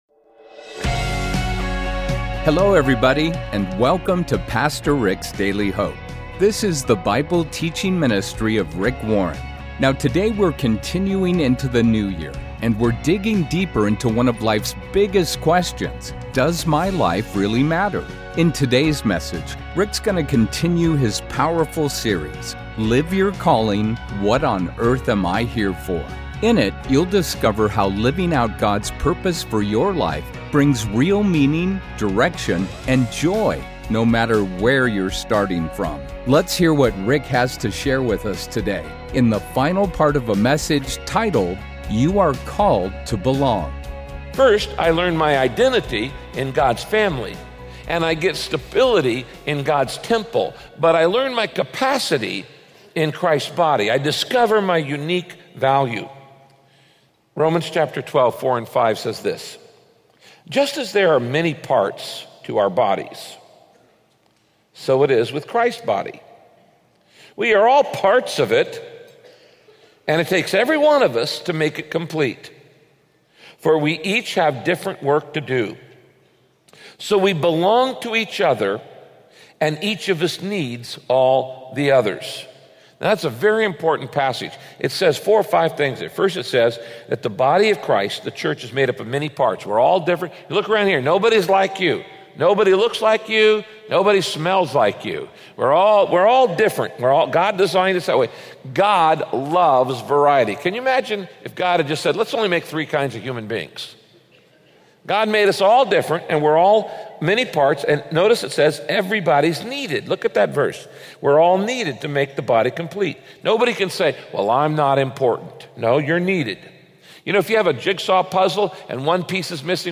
In this broadcast, Pastor Rick teaches that if you're a member of the family of God, your sin doesn't define you anymore and why that truth brings freedom to your life.